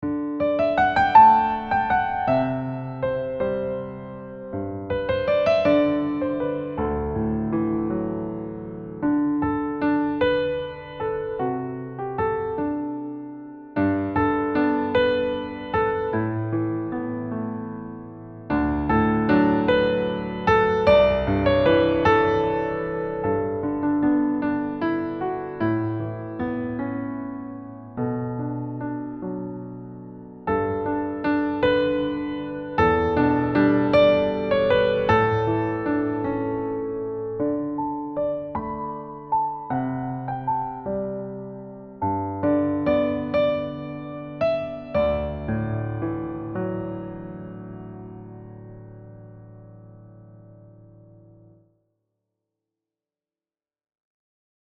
heartfelt early intermediate piano solo
Key: D Major
Time Signature: 6/8 (Dotted quarter note = 54)
Character: Lyrical, expressive, warm, Pop music like